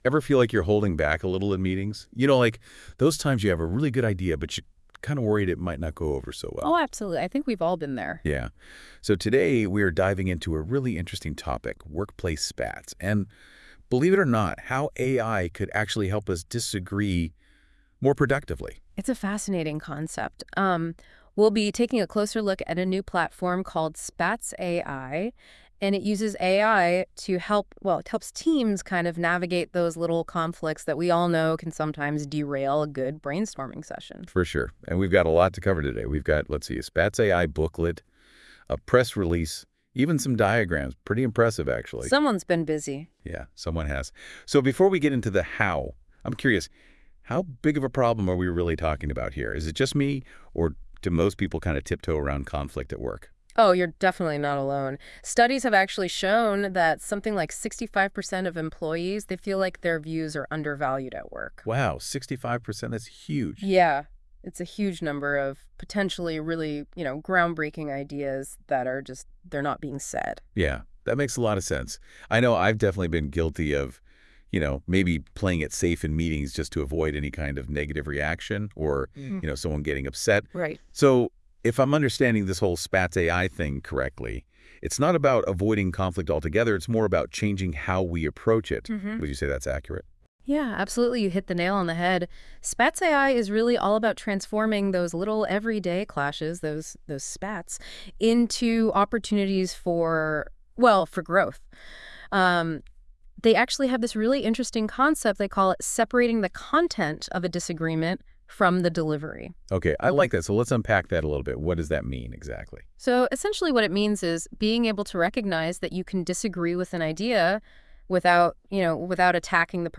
Welcome to the SpatzAI podcast (yes AI generated)